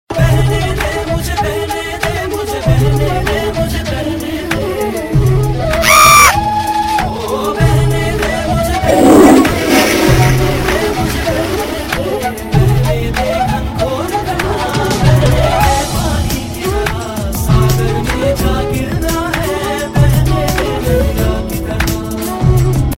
Versus Ringtones